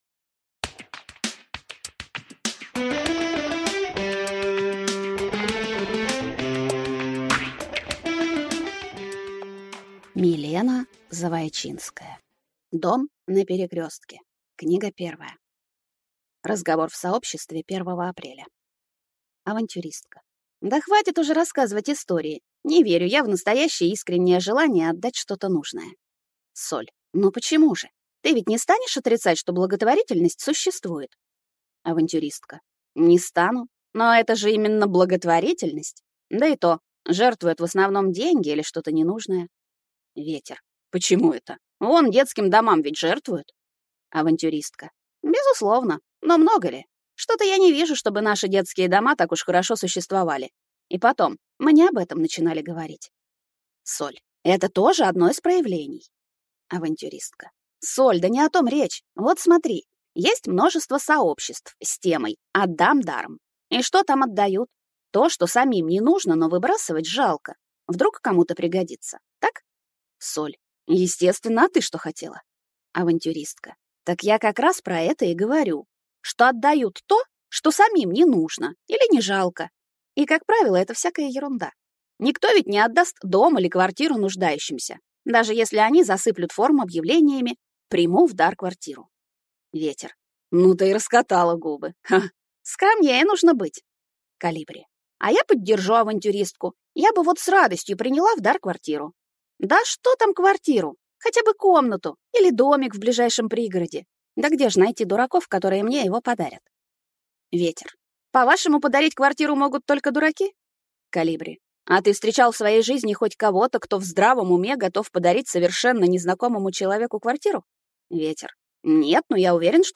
Аудиокнига Дом на перекрестке | Библиотека аудиокниг